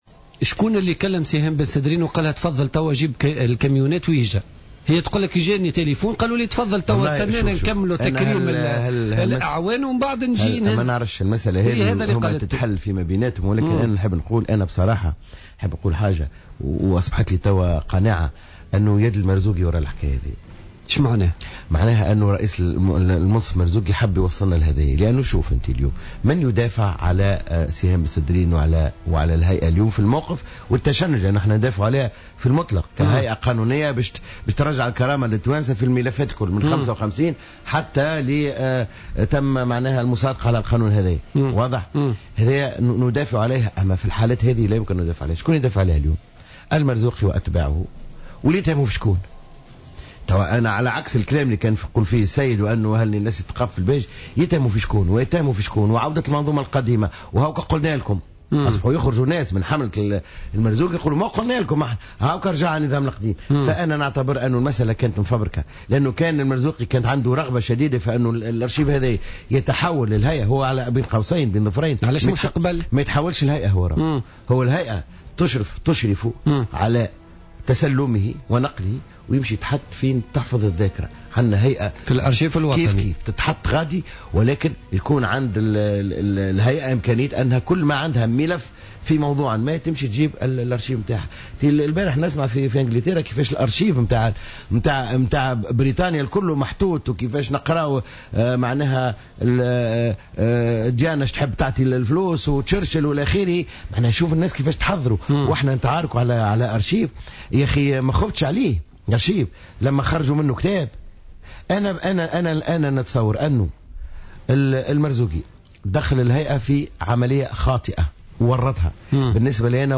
Le secrétaire général du parti Al Massar, Samir Taieb a déclaré lundi lors de son passage sur le plateau de Jawhara Fm que la présidente de l’instance « vérité et dignité », Sihem Ben Sedrine avait tort de se précipiter de saisir l’archive du Palais de Carthage soulignant que le moment était mal choisi.